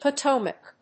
音節Po・to・mac 発音記号・読み方
/pətóʊmək(米国英語), pʌˈtəʊmʌk(英国英語)/